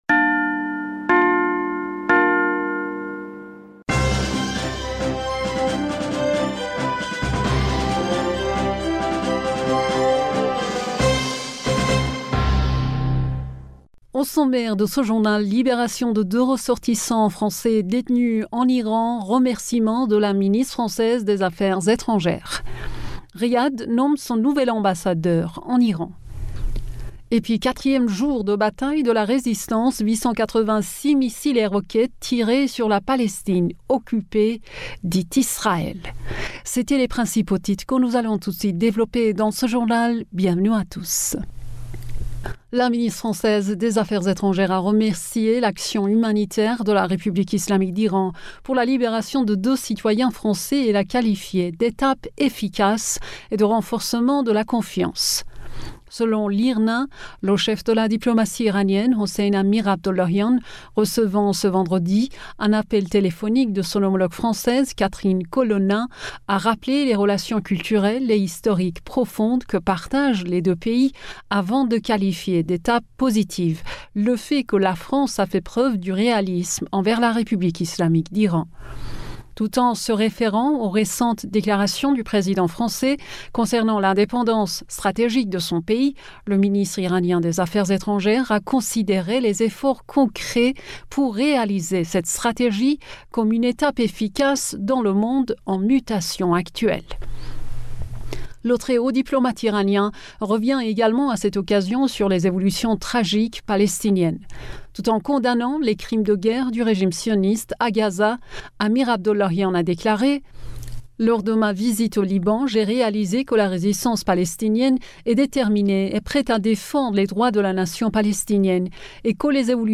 Bulletin d'information du 12 Mai 2023